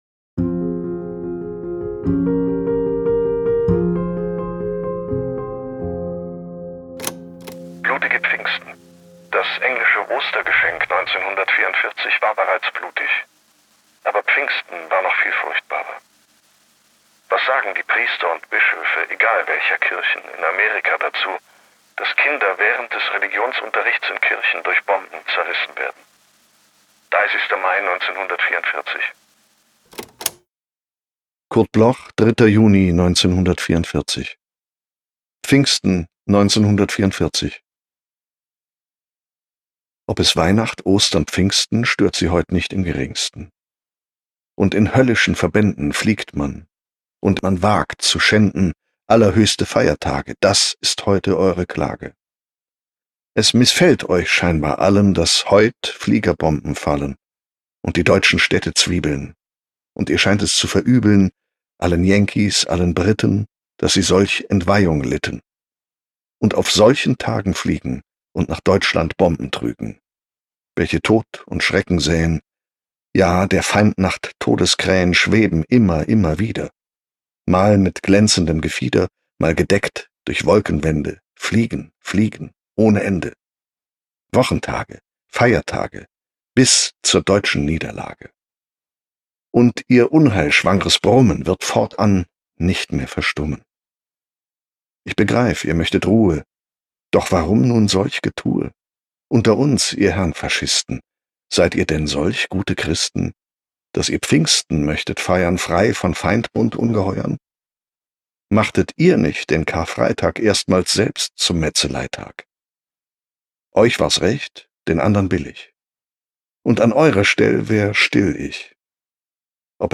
voorgedragen door Matthias Brandt
M.Brandt-Pfingsten-1944_NEU_mit-Musik.m4a